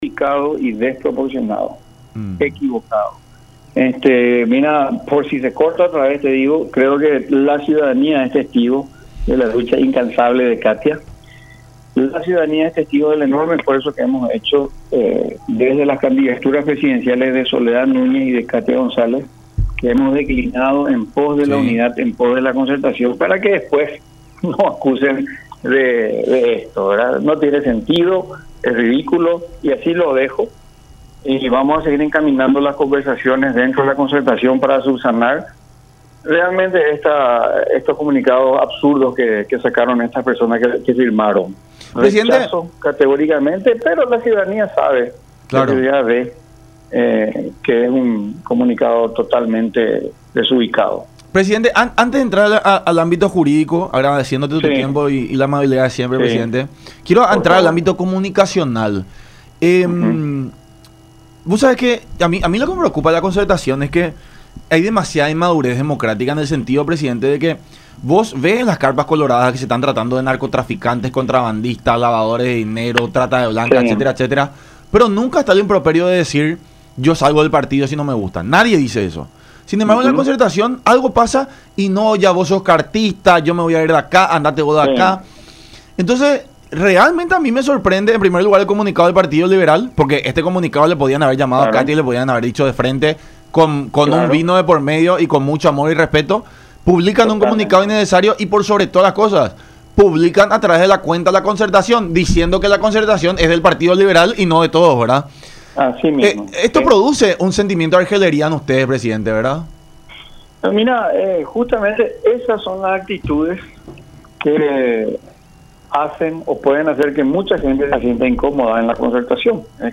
en diálogo con La Unión Hace La Fuerza por Unión TV y radio La Unión